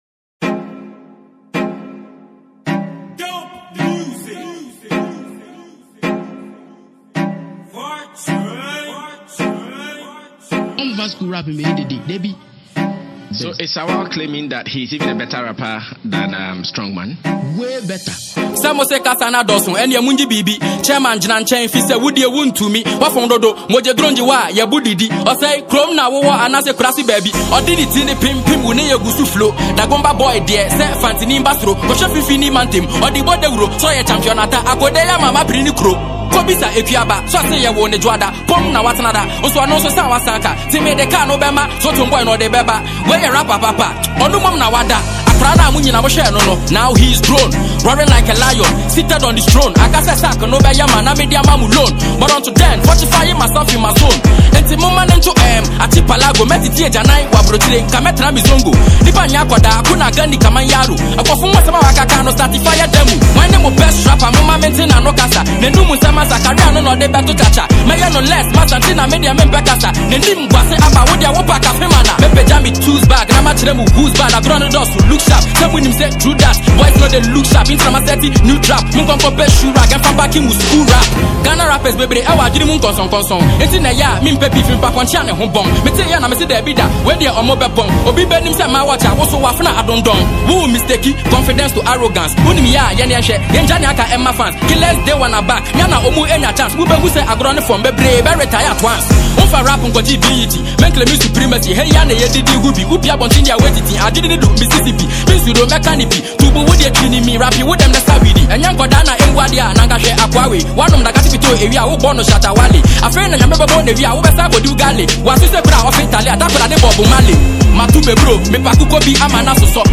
the sensational rapper
hiphop